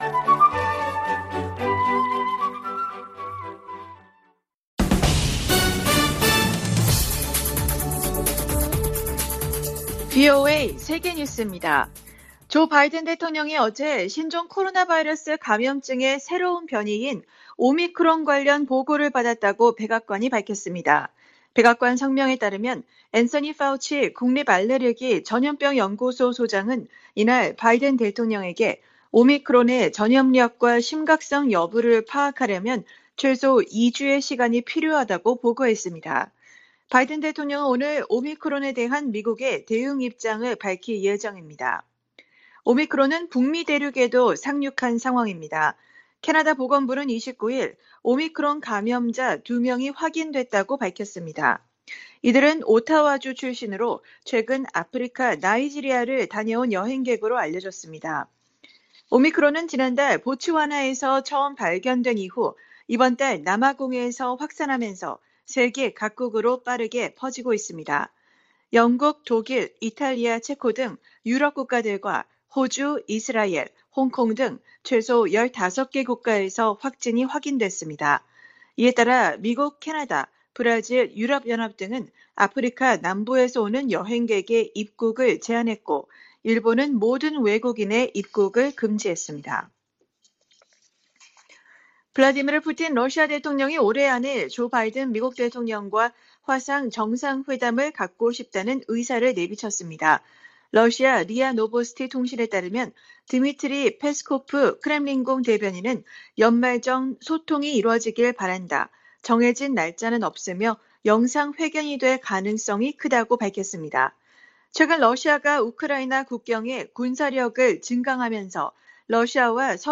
VOA 한국어 간판 뉴스 프로그램 '뉴스 투데이', 2021년 11월 29일 3부 방송입니다. 북한이 신종 코로나바이러스 감염증의 새로운 변이종인 ‘오미크론’의 등장에 방역을 더욱 강화하고 있습니다. 북한의 뇌물 부패 수준이 세계 최악이라고, 국제 기업 위험관리사가 평가했습니다. 일본 정부가 추경예산안에 68억 달러 규모의 방위비를 포함시켰습니다.